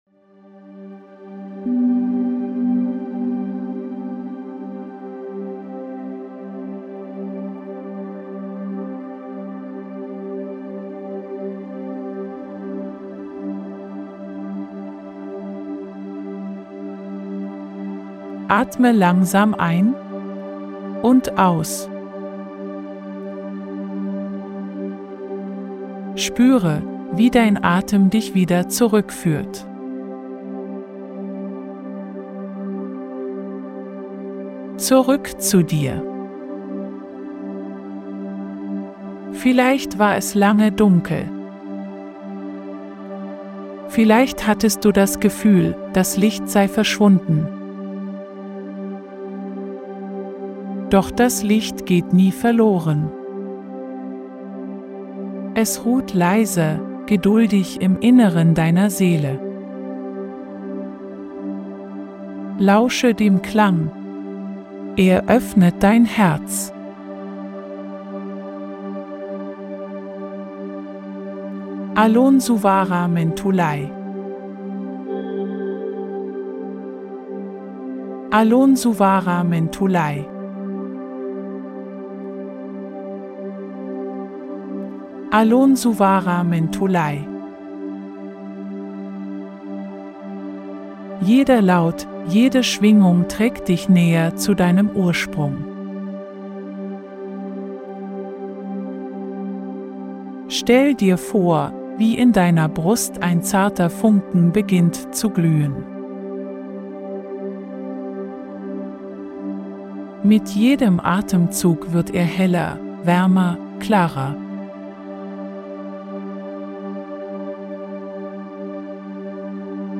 🎧 Die Audio-Meditation zum